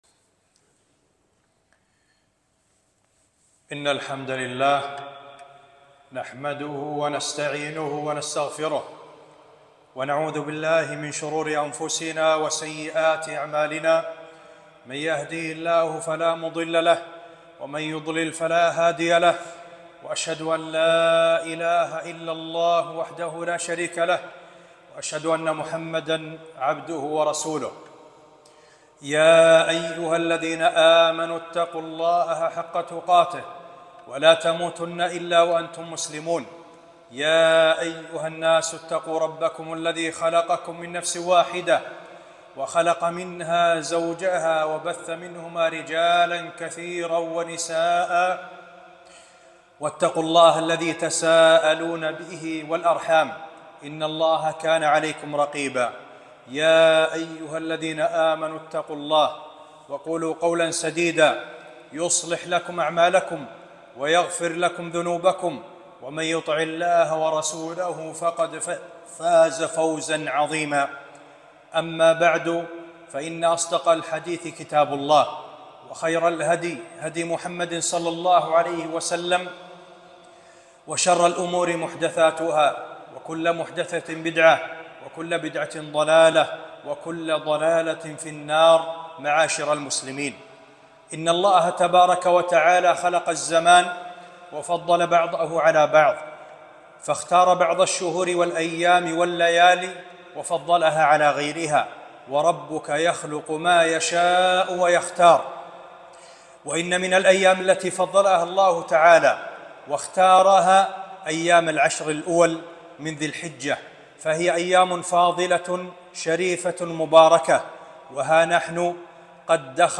خطبة - فضل عشر ذي الحجة